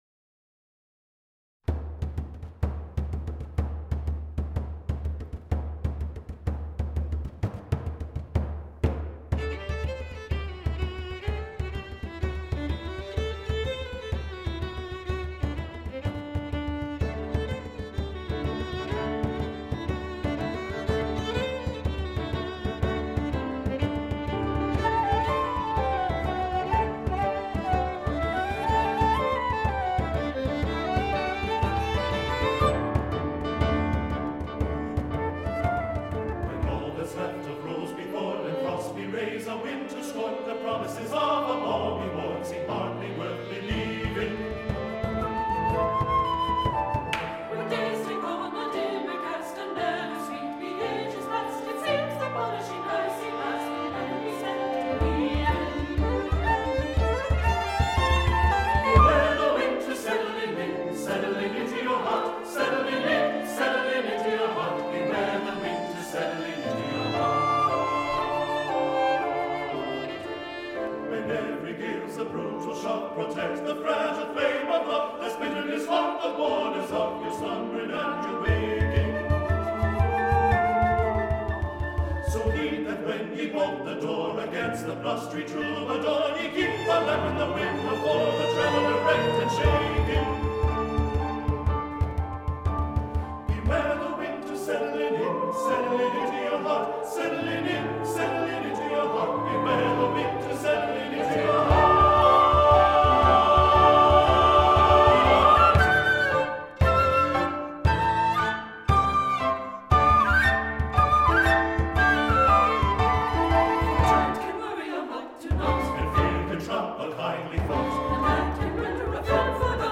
SATB, opt. children’s choir, fl, bodhran, vln, pno
The bodhrán part is a traditional Irish jig played ad lib.